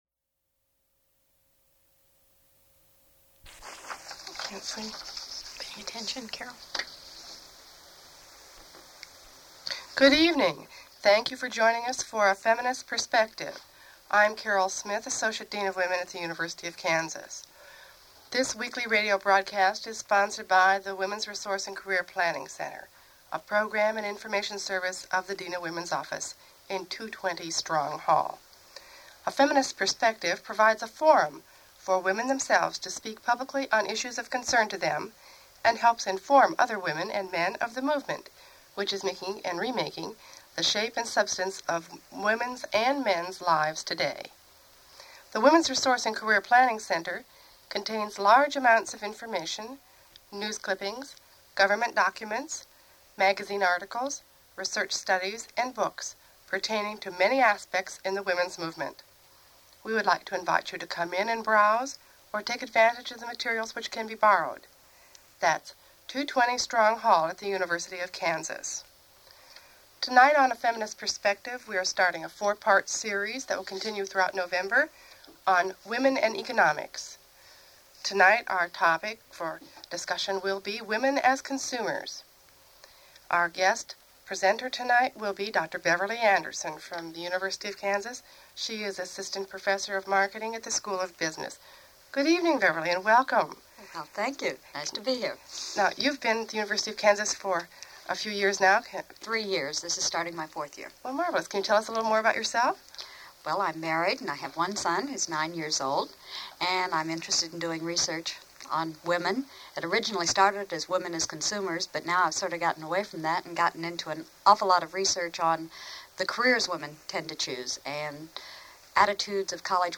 Feminist Perspective radio program
Radio talk shows